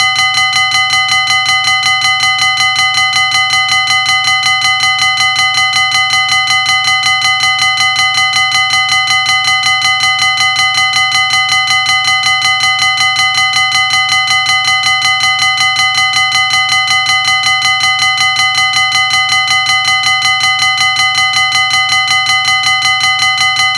elektronische overwegbel klinkt?